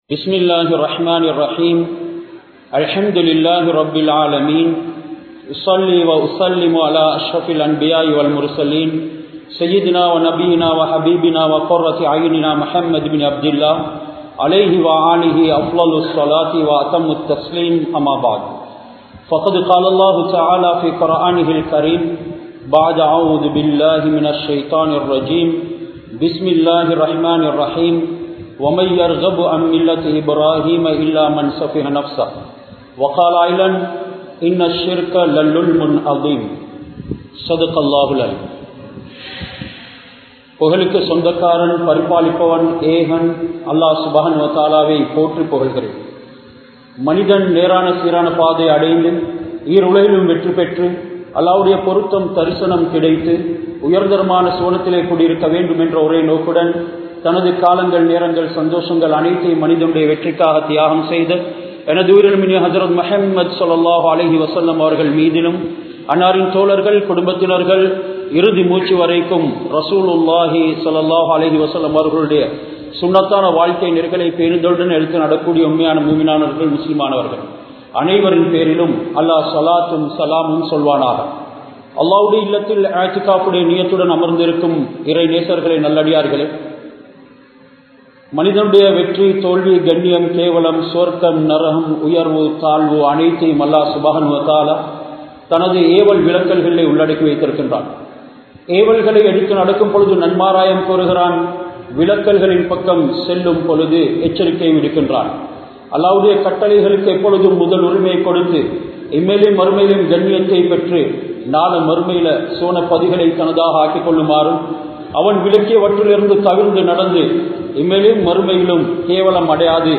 Ibrahim(Alai)Avarhalin Eahaththuvam (இப்றாஹிம்(அலை)அவர்களின் ஏகத்துவம்) | Audio Bayans | All Ceylon Muslim Youth Community | Addalaichenai
Kattukela Jumua Masjith